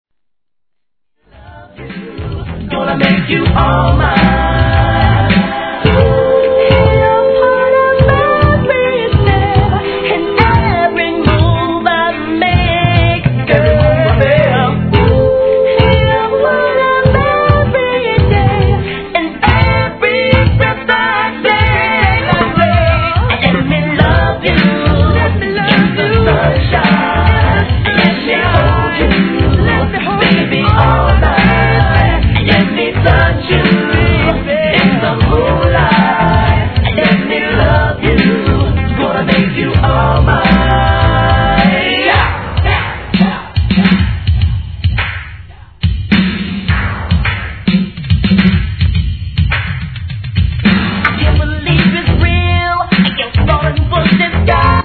HIP HOP/R&B
ジャケ通りの爽やか系80'sフレイヴァー溢れるヴォーカル＆ラップグループ！